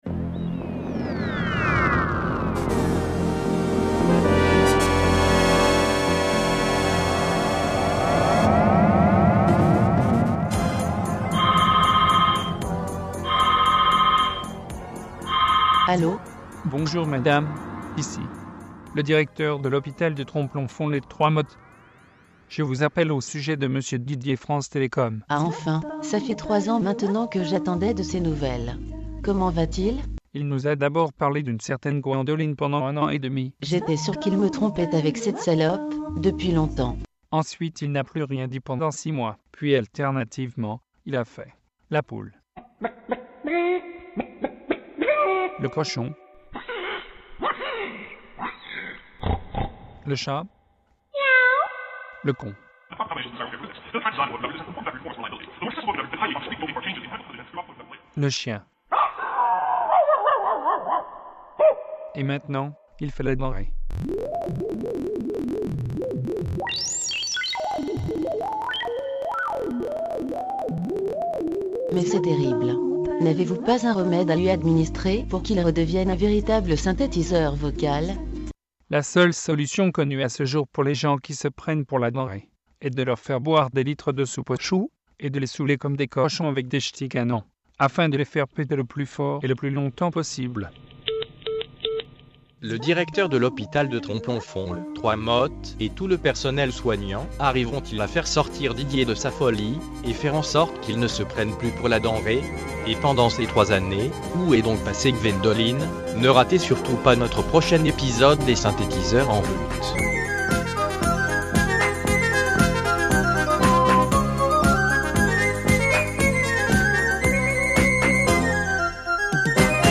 la petite musique de fin :love: